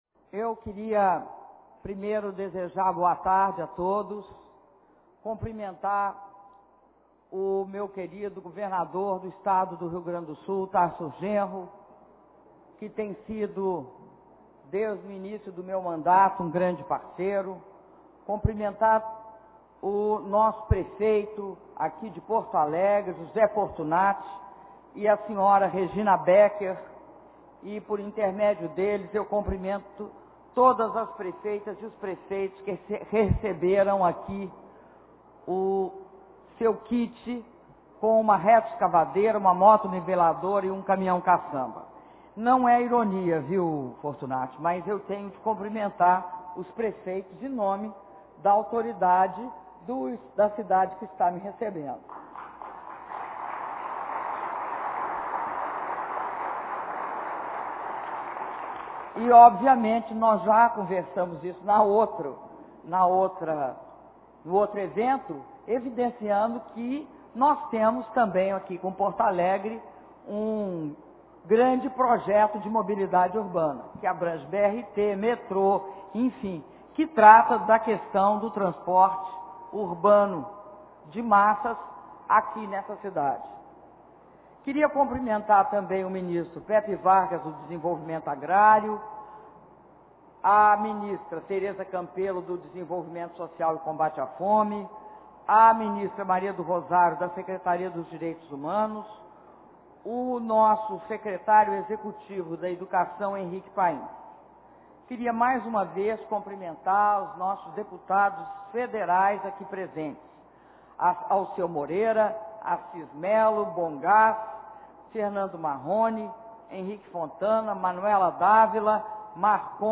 Discurso da Presidenta da República, Dilma Rousseff, durante cerimônia de entrega de retroescavadeiras, motoniveladoras e ônibus escolares a prefeitos do Rio Grande do Sul - Porto Alegre/RS
Porto Alegre – RS, 12 de abril de 2013